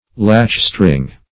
Latchstring \Latch"string`\, n.